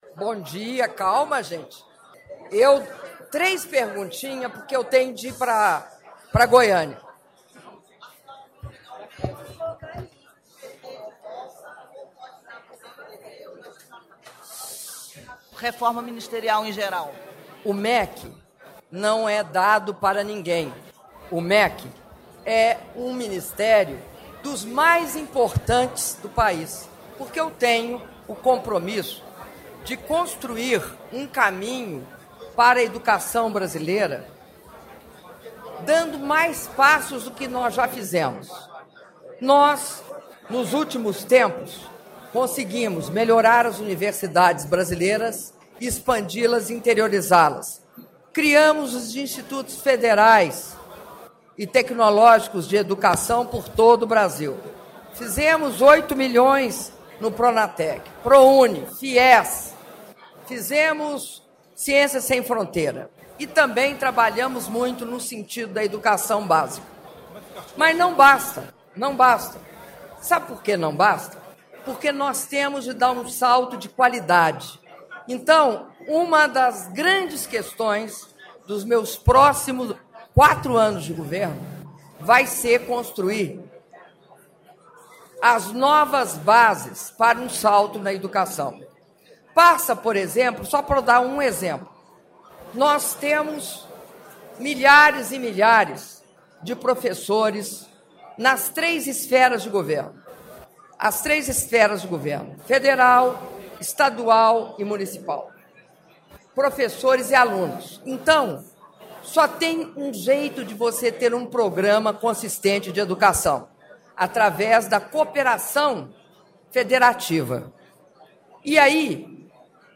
Áudio da entrevista coletiva concedida pela presidenta Dilma Rousseff após cerimônia de anúncio de Medidas de Modernização do Futebol - Brasília (06mim42s)